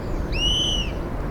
Call recorded Encarnación, Departamento Itapúa